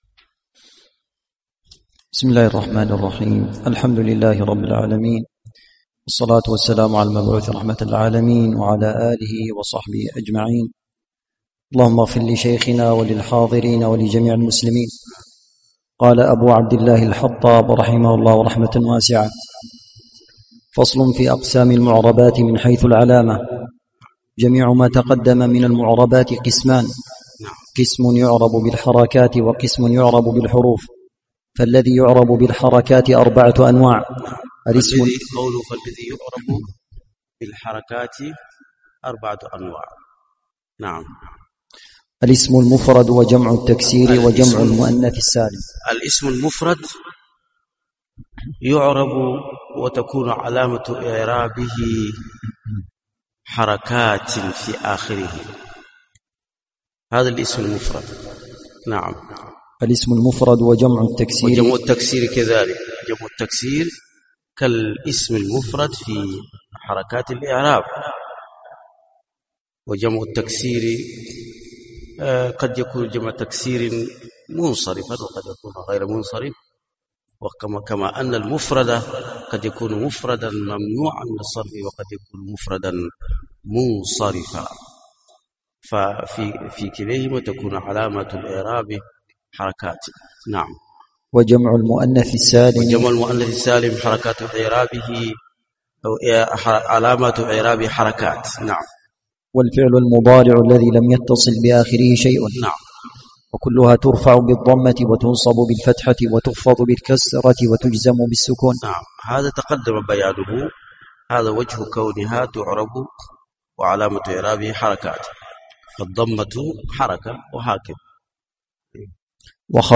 الدرس الثالث